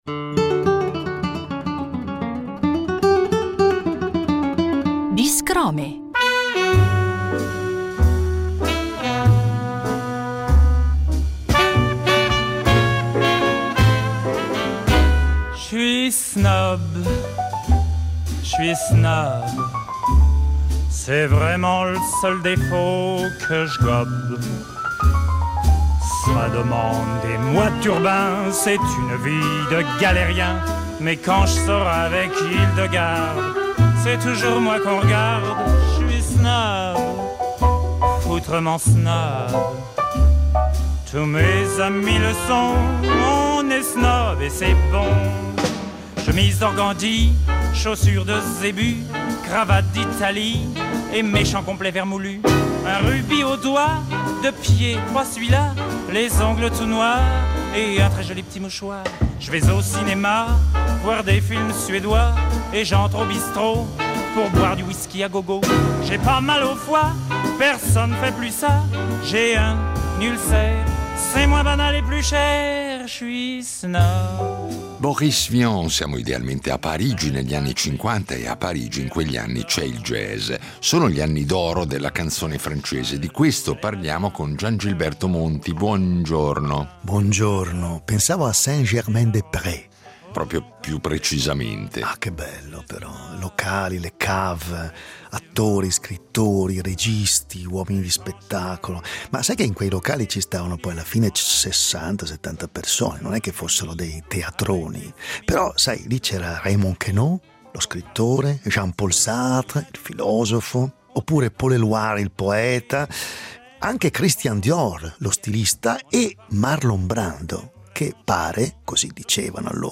Il disco, allegato ad un volume dal quale abbiamo ricalcato il titolo di questa serie radiofonica, celebra i nomi più amati di quel periodo, da Edith Piaf a Jacques Brel, da Françoise Hardy a Boris Vian.